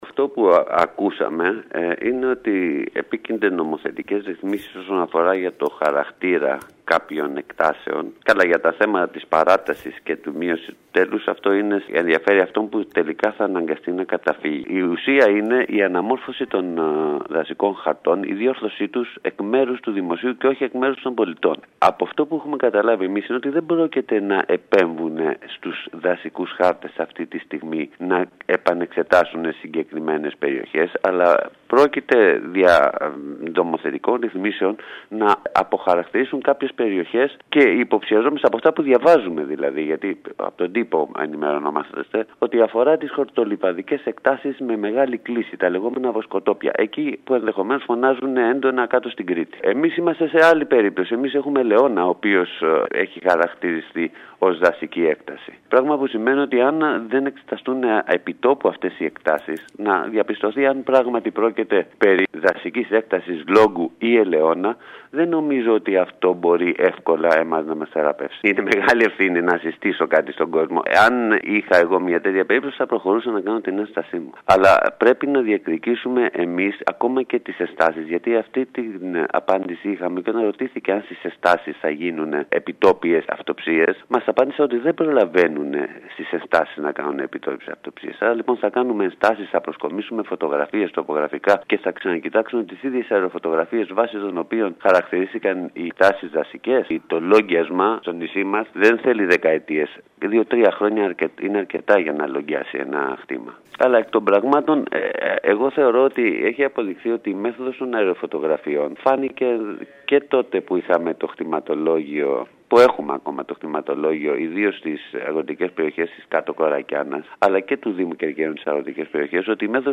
Μιλώντας σήμερα στην ΕΡΑ ΚΕΡΚΥΡΑΣ